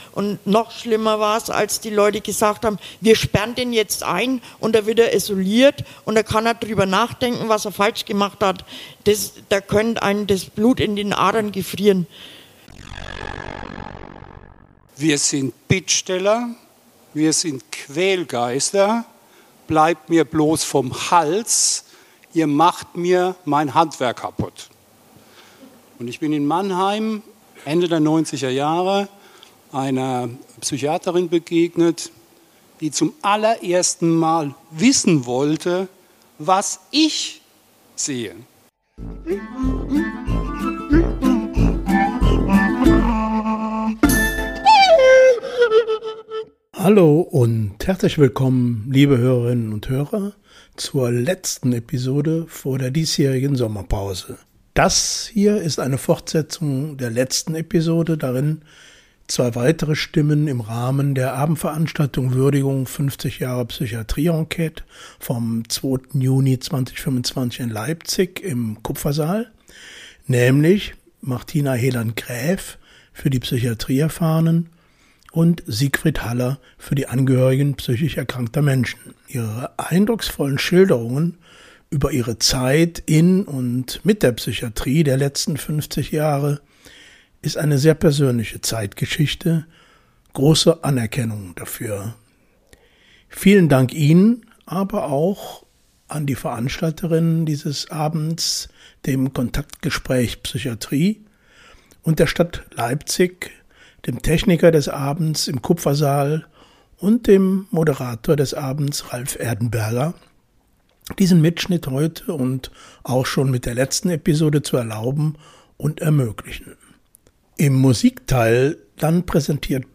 Bereichert mit Interviews von Insidern aus den verschiedensten Perspektiven. In jeder Folge wird zudem ein Song aus der Rock- und Popgeschichte gespielt und der jeweilige Künstler vorgestellt.